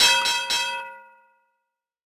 timeUp.ogg